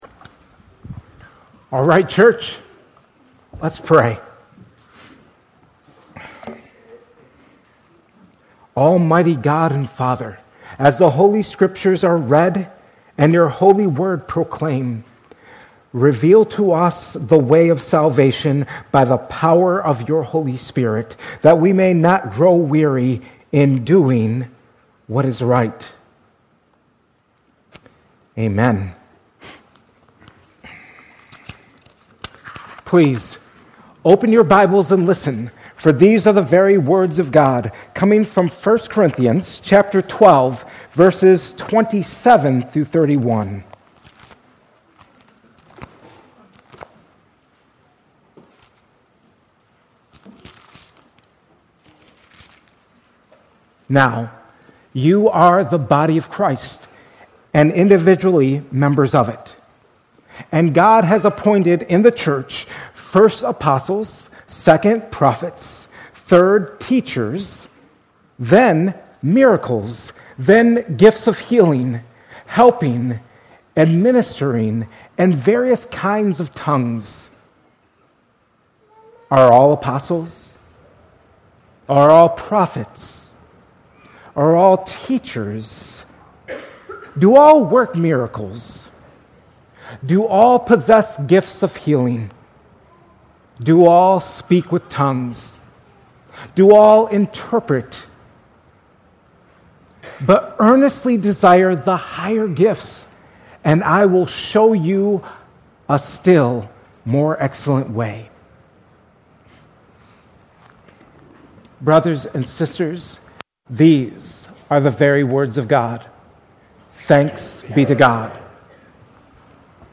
2025 at Cornerstone Church in Pella, Iowa.